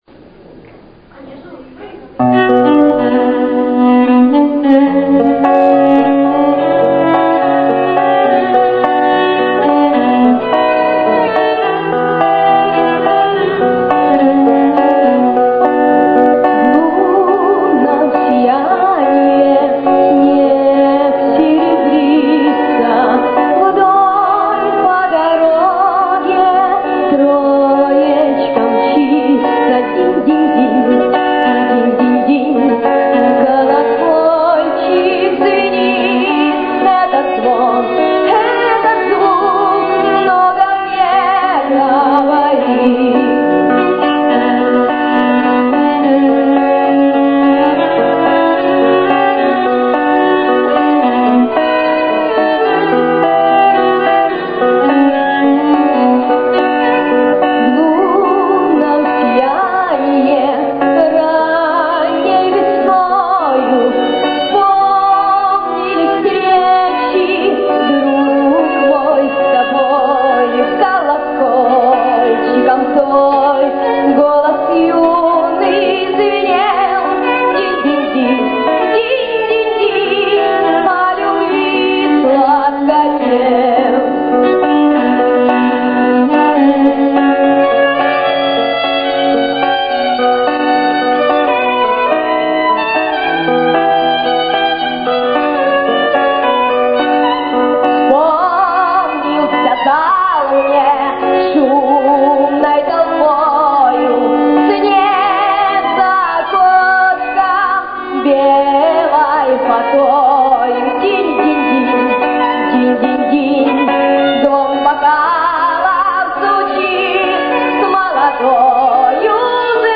Авторская песня на Камчатке
Концерт в поддержку